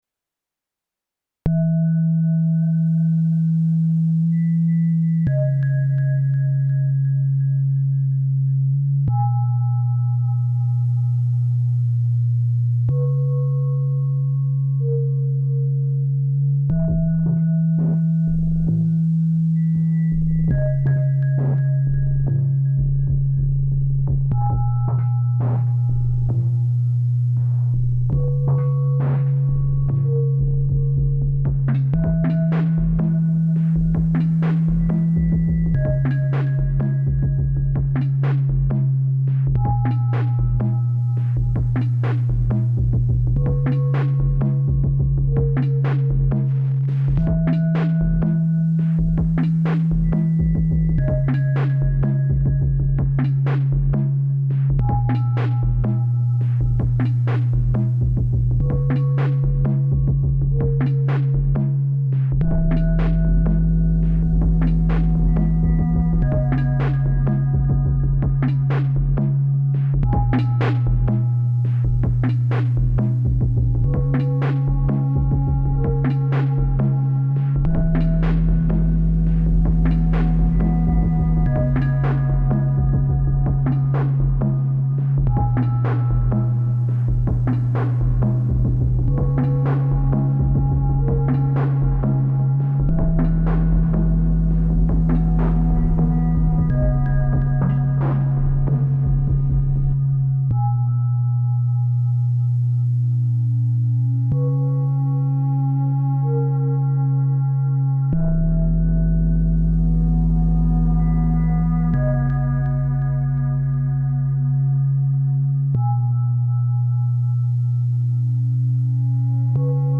Slow Monday beats on the Rytm.